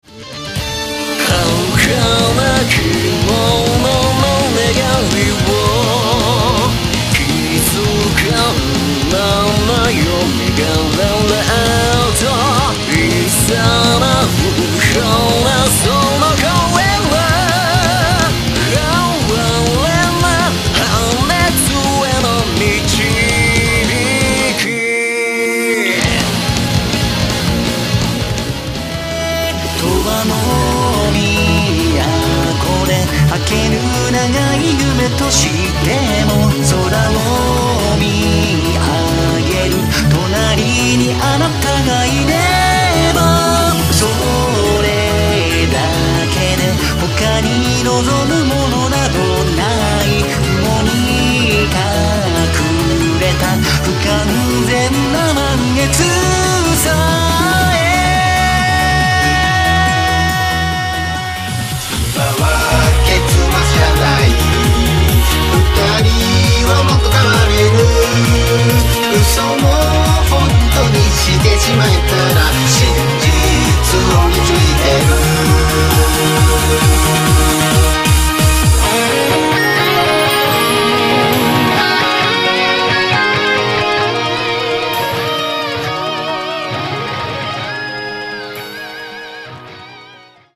ギター